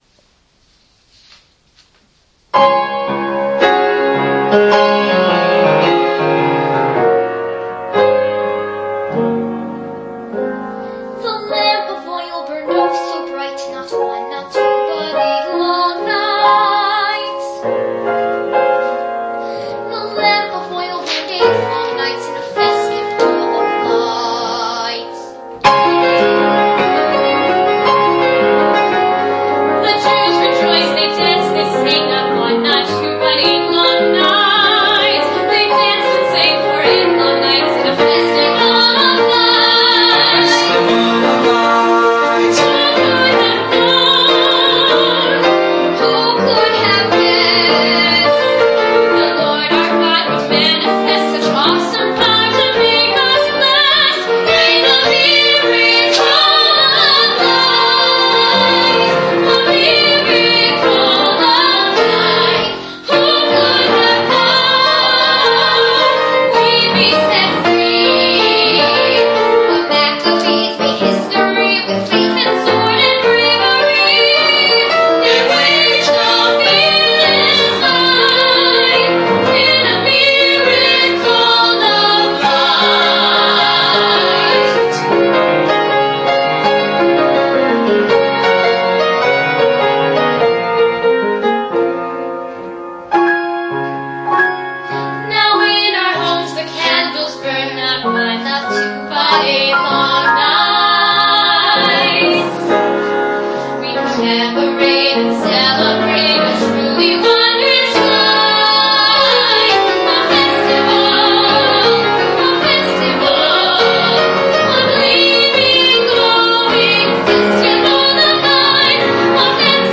Cabaret Songs
Piano